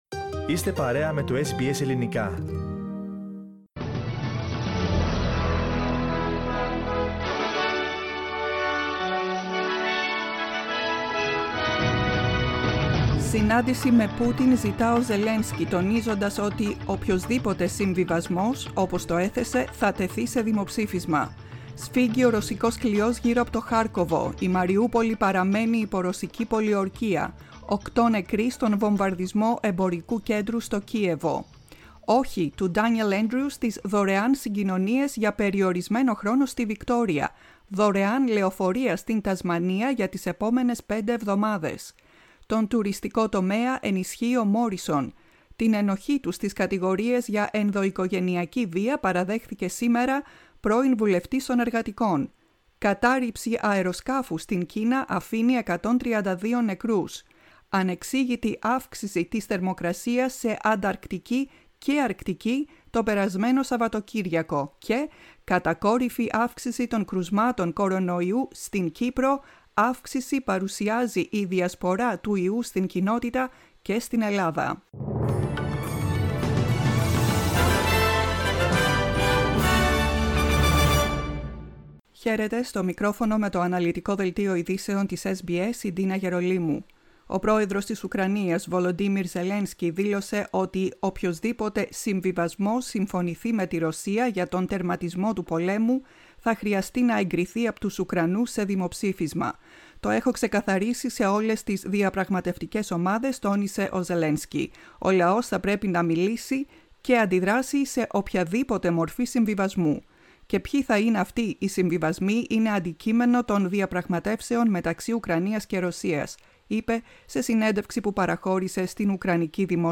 News bulletin in Greek, 22.03.22